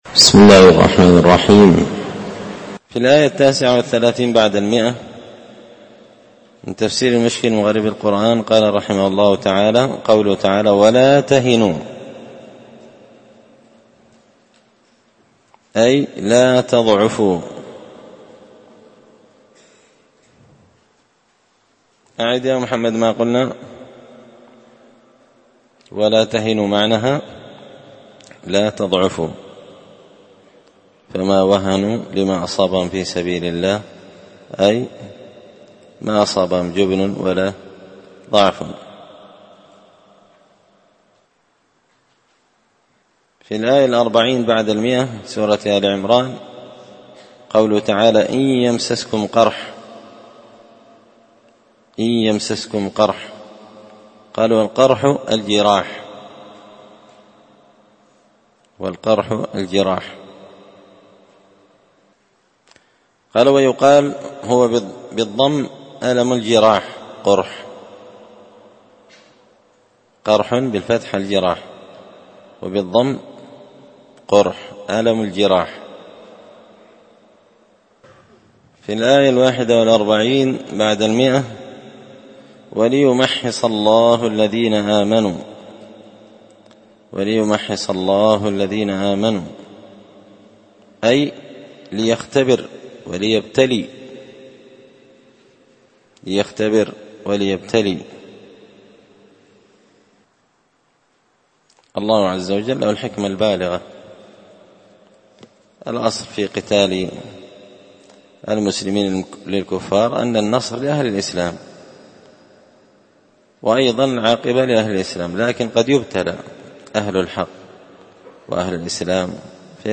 تفسير المشكل من غريب القرآن الدرس 72
دار الحديث بمسجد الفرقان ـ قشن ـ المهرة ـ اليمن